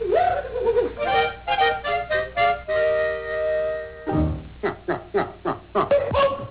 Curly’s Famous Nyuk-Nyuk Laugh